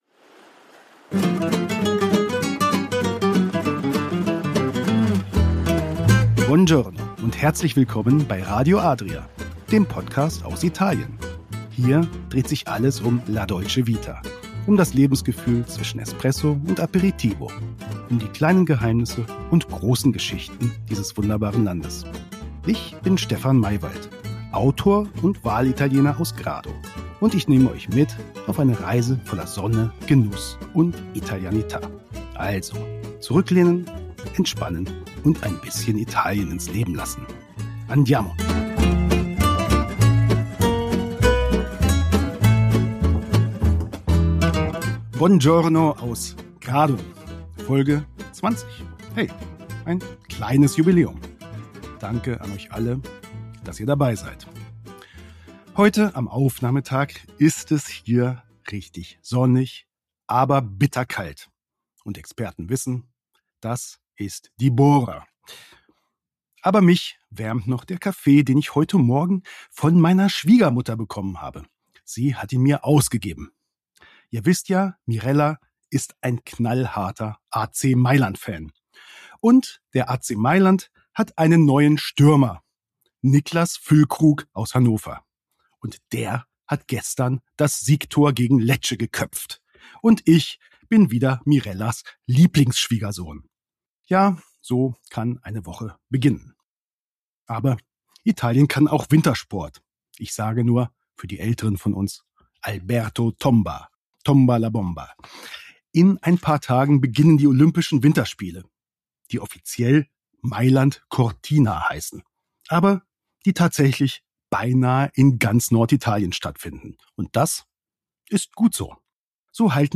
Das perfekte (italienische!) Frühstück: ein Interview